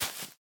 Minecraft Version Minecraft Version snapshot Latest Release | Latest Snapshot snapshot / assets / minecraft / sounds / block / spore_blossom / step3.ogg Compare With Compare With Latest Release | Latest Snapshot